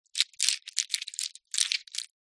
Звуки фисташек
Здесь собраны уникальные записи: от мягкого шелеста скорлупы до насыщенного хруста при разламывании.
Шелест фисташек в ладони со скорлупой